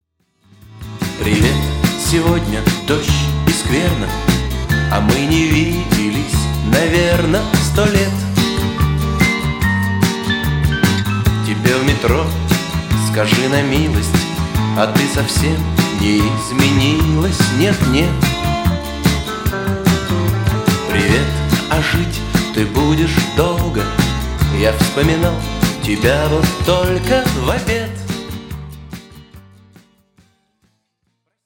80-90-е инструментальные
мужской голос